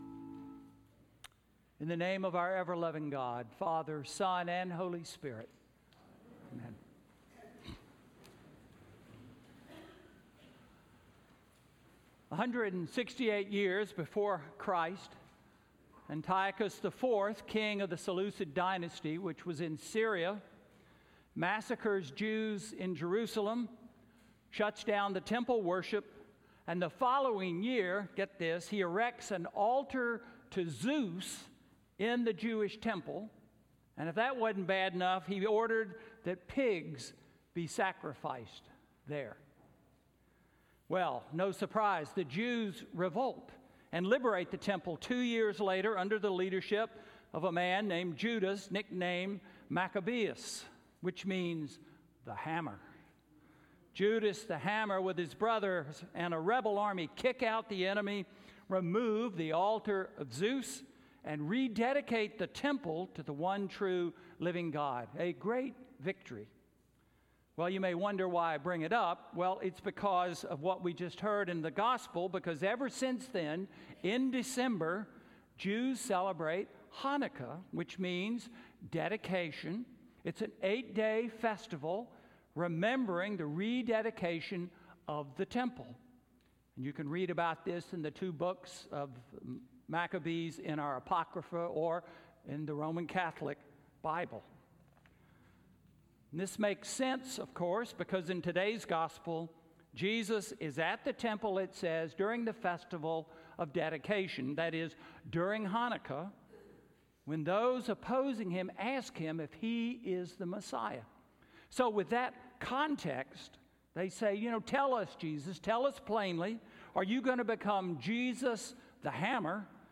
Sermon–The Jesus Movement Strategy?–May 12, 2019